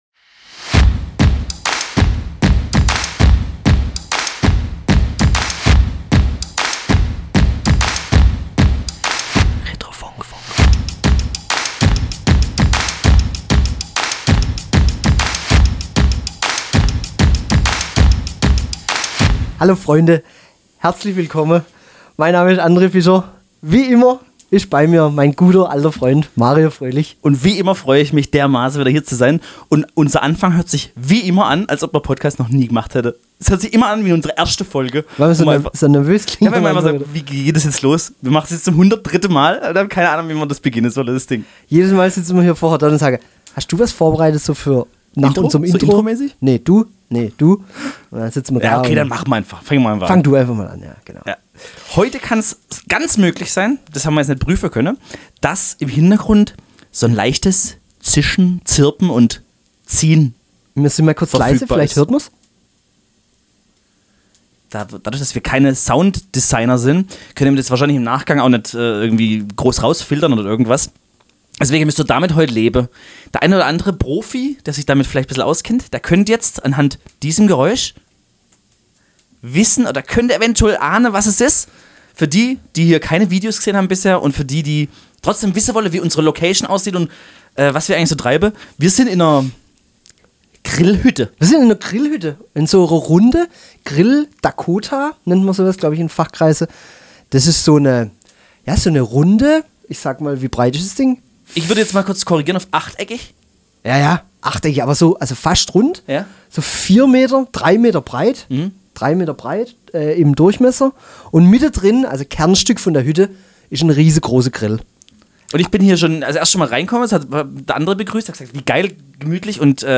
Beschreibung vor 2 Monaten In rustikaler und gemütlicher Atmosphäre gibt es diese Woche die Fantastic Four der letzten Woche auf die Ohren, kombiniert mit Talk all around the World.
Ganz viel Spaß bei Folge 103 und sorry für die drei Piepser - war aber wirklich nicht schön!